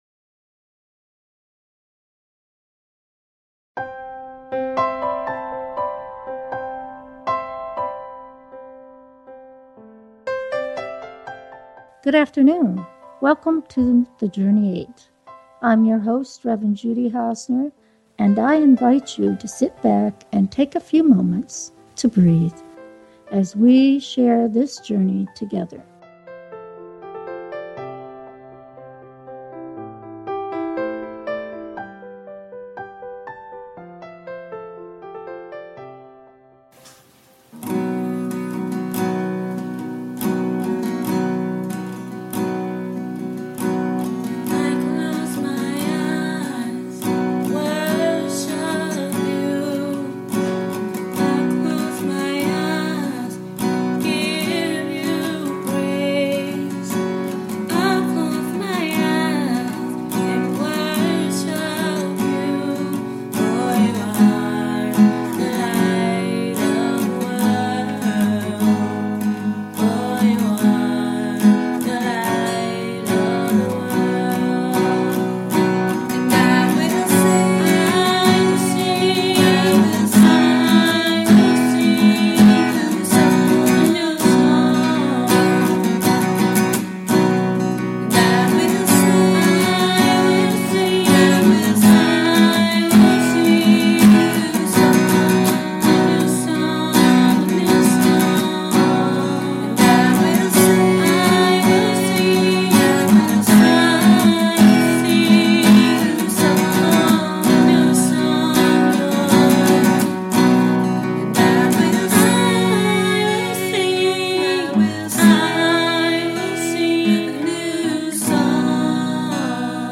The Journey 8 is a Christian based talk show that crosses the barriers of fear and hatred to find understanding and tolerance for all of God’s children.